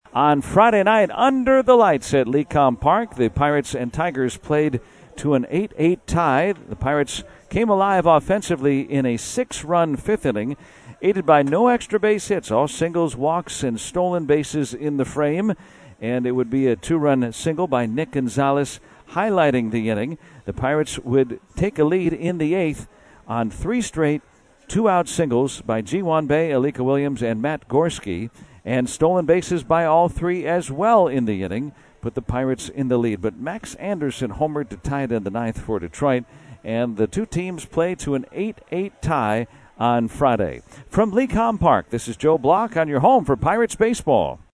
3-14-recap.mp3